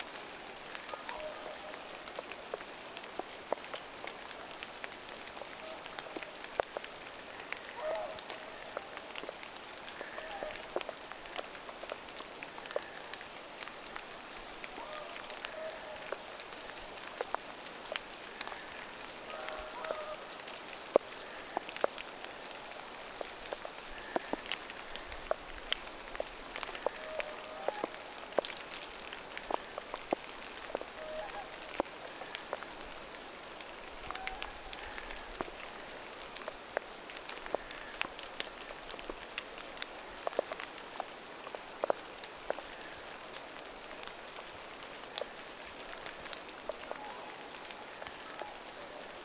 nuori laulujoutsen huutaa sateessa
Taitaa siellä olla vielä aikuinen.
nuori_laulujoutsen_huutaa_sateessa.mp3